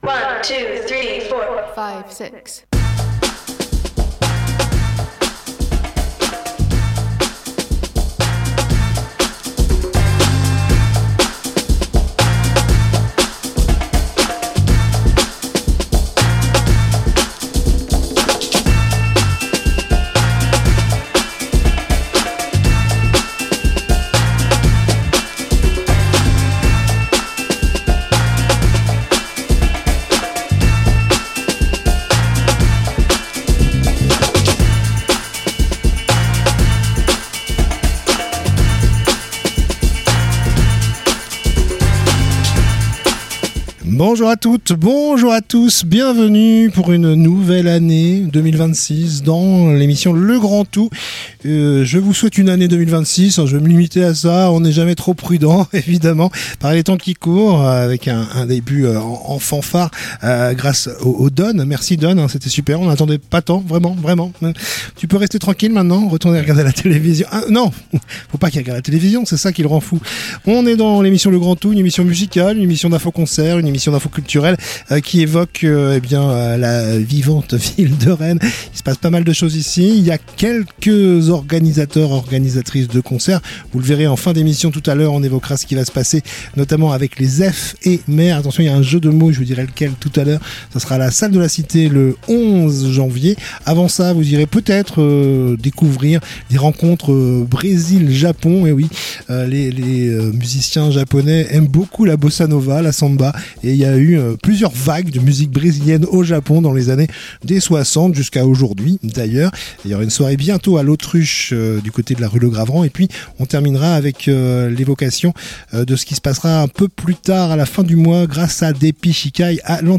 itv musique